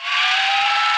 ciwsSpinup.ogg